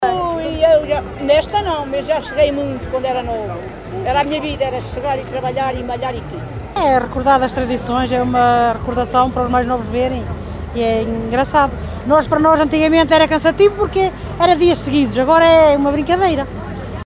vox-pop-pessoas-.mp3